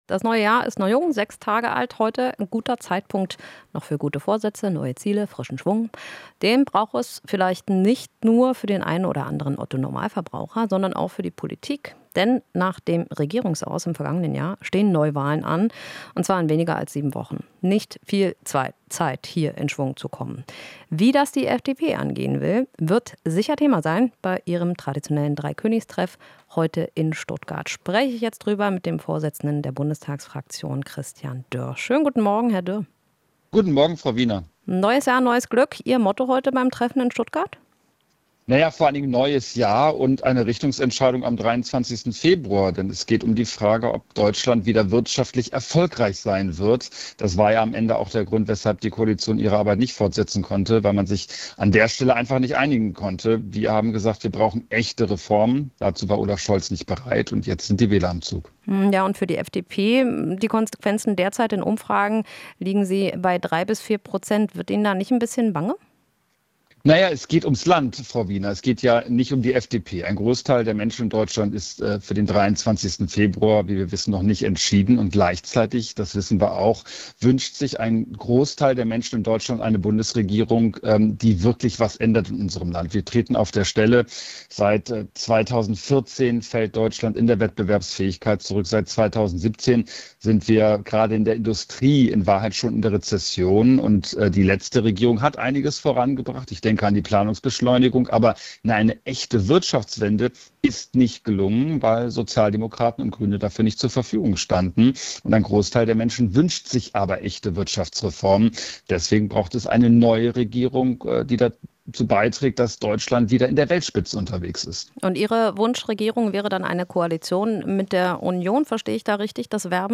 Interview - FDP-Dreikönigstreffen: Dürr fordert "echte Wirtschaftsreformen"